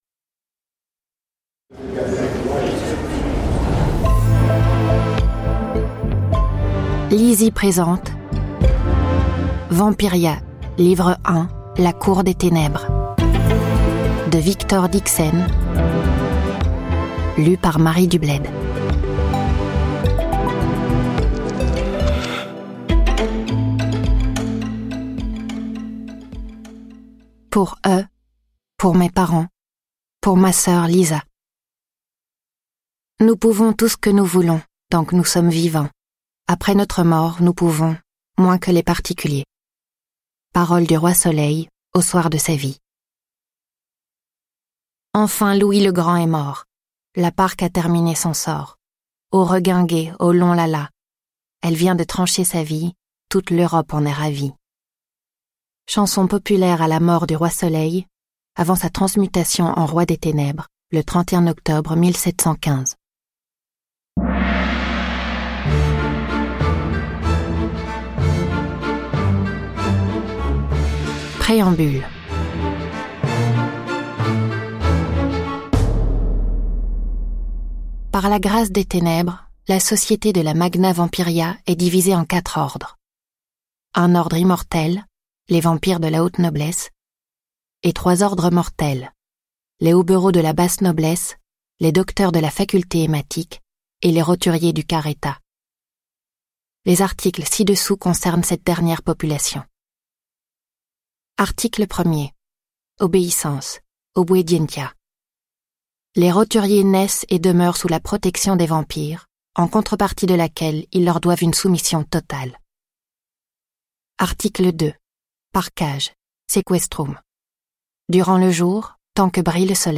Découvrez en bonus du livre audio la danse ensorcelante de la Gigue sans repos mise en musique !